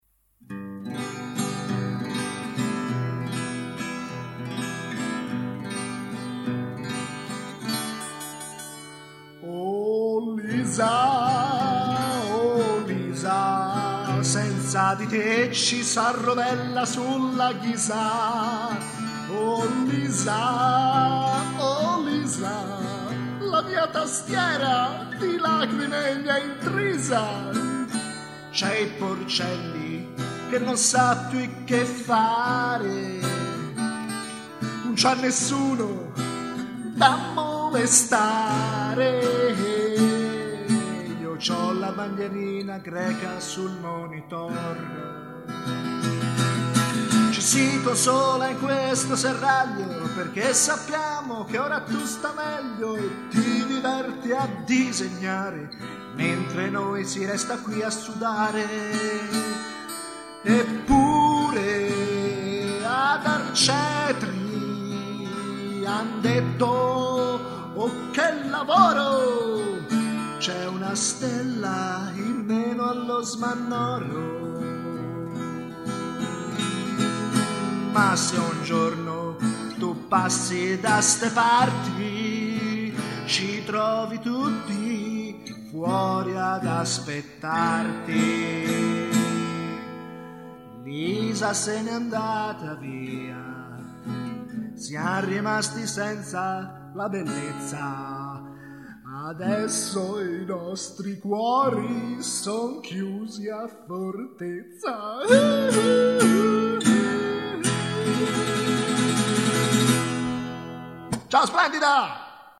Canzone leggera e non molto studiata